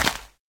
Minecraft / dig / grass3.ogg
Current sounds were too quiet so swapping these for JE sounds will have to be done with some sort of normalization level sampling thingie with ffmpeg or smthn 2026-03-06 20:59:25 -06:00 8.4 KiB Raw History
grass3.ogg